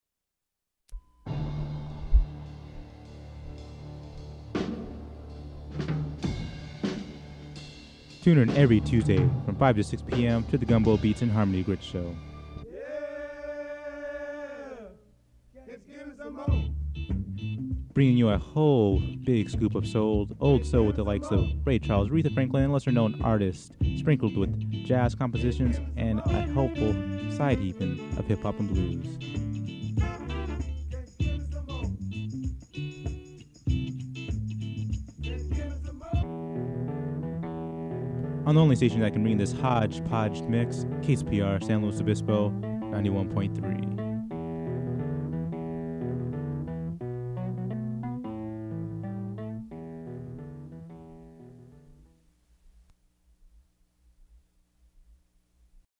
Gumbo Beats and Harmony Grits, November 5, 1996 [advertisement]
Form of original Audiocassette